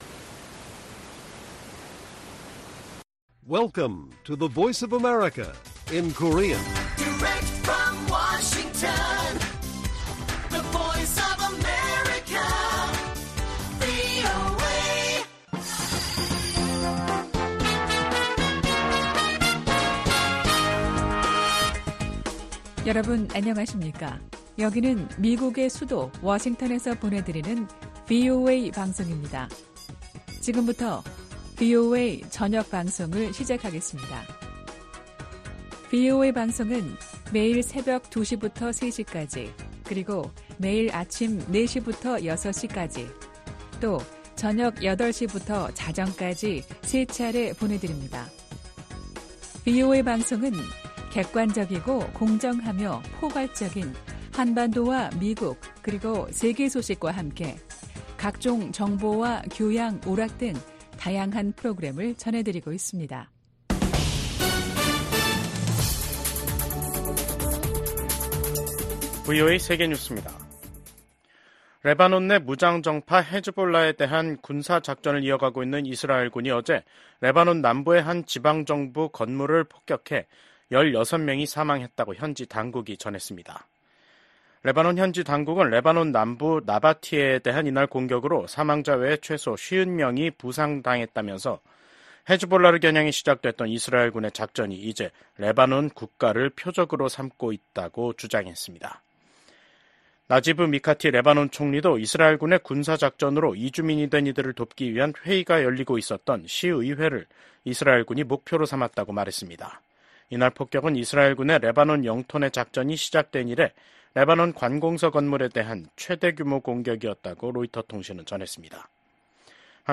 VOA 한국어 간판 뉴스 프로그램 '뉴스 투데이', 2024년 10월 17 1부 방송입니다. 북한이 한국을 적대국가로 규정한 내용을 담아 헌법을 개정한 것으로 파악됐습니다. 한국 정부는 반통일 반민족적 행위라고 규탄했습니다. 캄보디아 정부가 북한을 방문했던 선박과 이 선박이 싣고 있던 석탄을 압류했습니다.